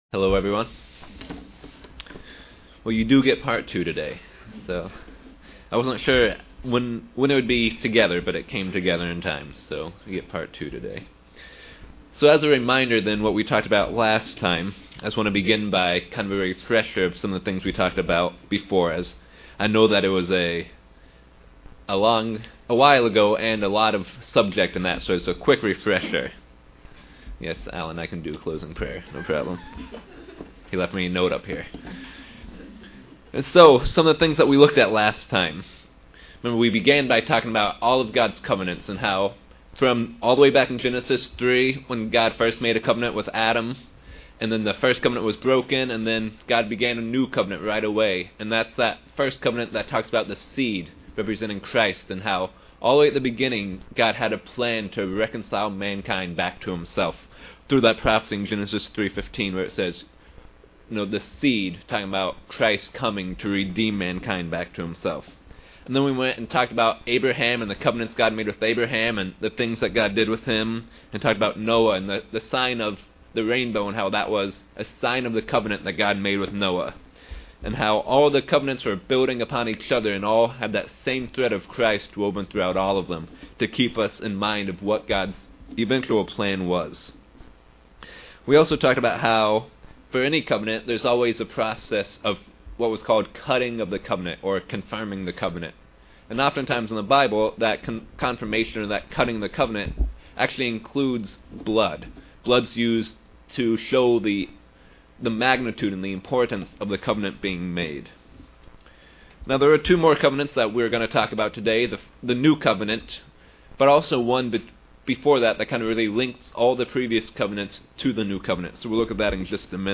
Given in York, PA
UCG Sermon Studying the bible?